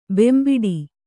♪ bembiḍi